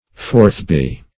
forthby - definition of forthby - synonyms, pronunciation, spelling from Free Dictionary Search Result for " forthby" : The Collaborative International Dictionary of English v.0.48: Forthby \Forth`by"\, adv.